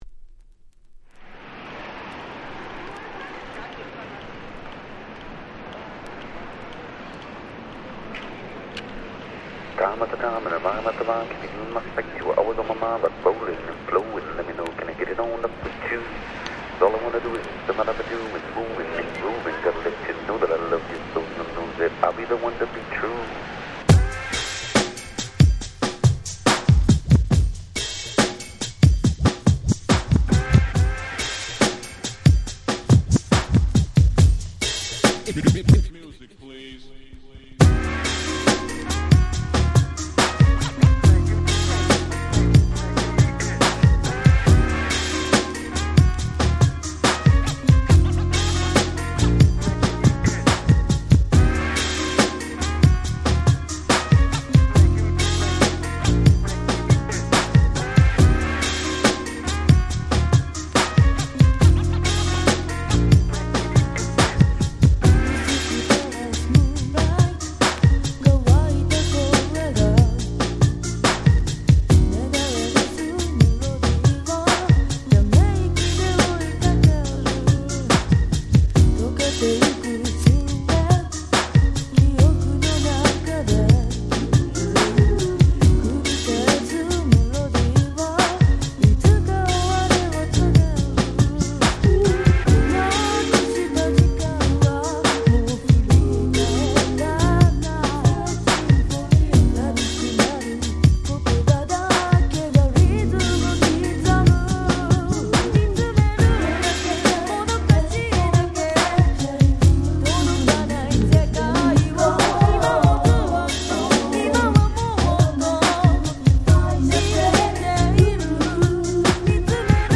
97' 謎のマイナー女性Vocal Japanese R&B !!
しかも内容がGround Beat風となれば、、、